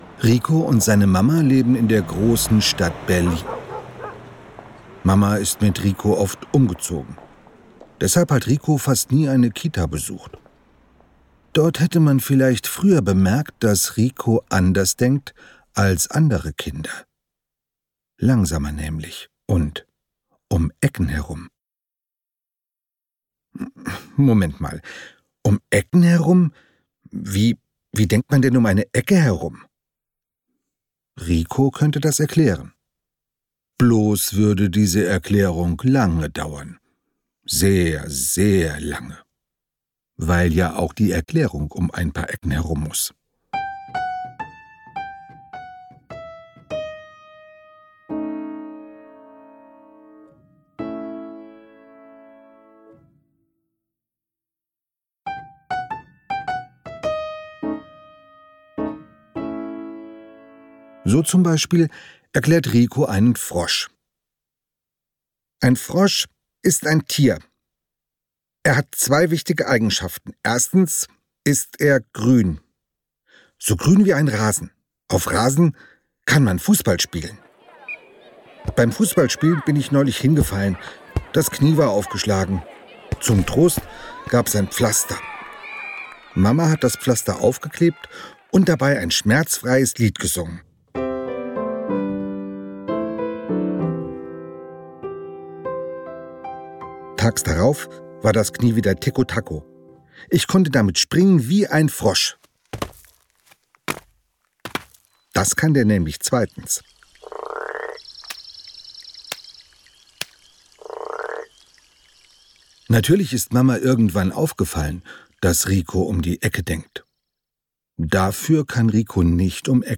Andreas Steinhöfel spricht seine Bücher
Die Geschichten werden vom Autor selbst gelesen.